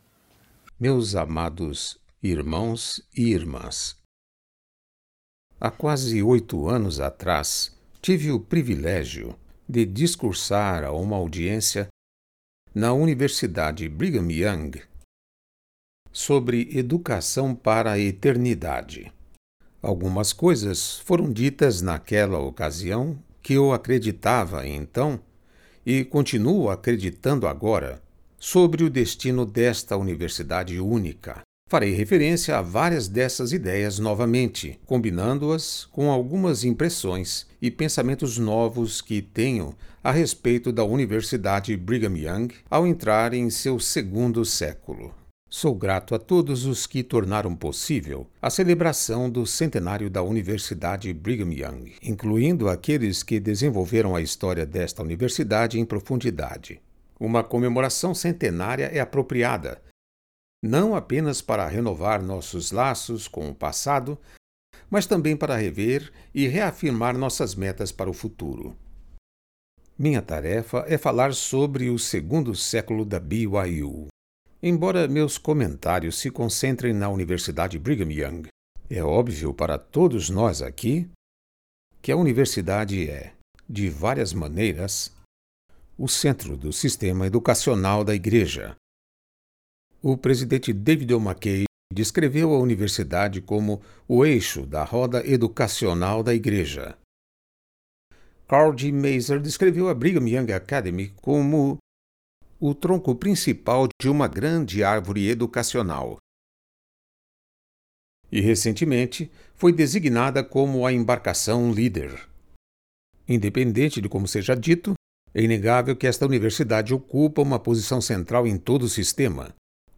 Audio recording of O segundo século da Universidade Brigham Young by Spencer W. Kimball
Presidente de A Igreja de Jesus Cristo do Santos dos Últimos Dias